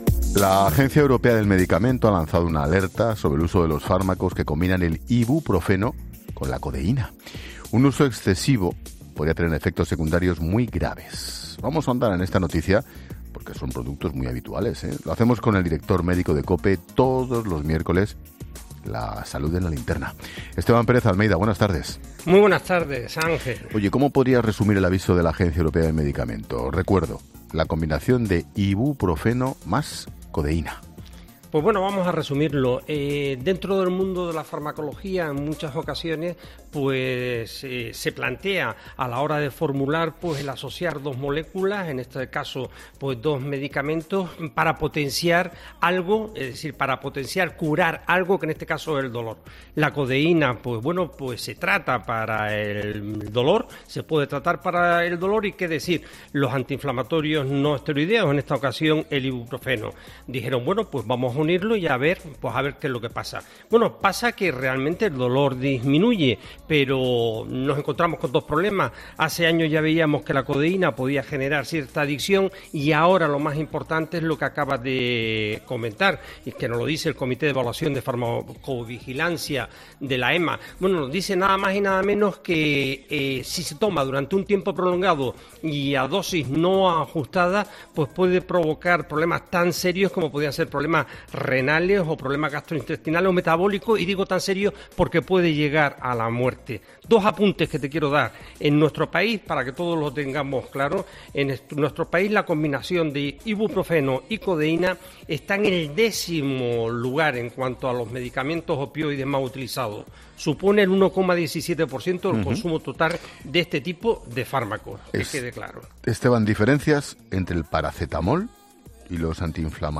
analiza con Ángel Expósito la alerta de la EMA y y cuáles son las diferencias con el Paracetamol
ha pasado por los micrófonos de 'La Linterna' para aclarar cuáles son los efectos secundarios de los fármacos que combinan la Codeína con el Ibuprofeno